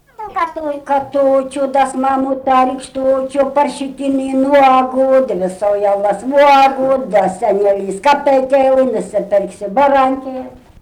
smulkieji žanrai
Ryžiškė
vokalinis